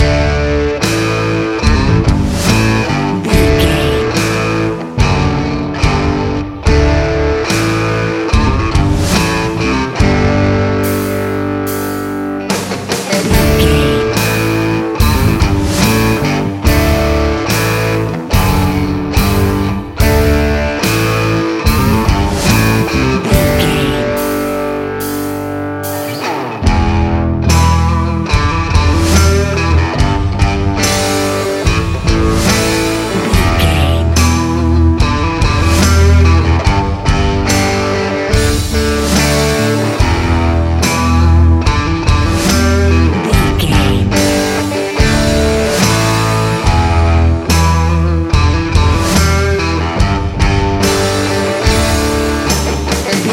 Epic / Action
Uplifting
Aeolian/Minor
bass guitar
electric guitar
drum machine
percussion
aggressive
intense
powerful
groovy
driving
energetic
heavy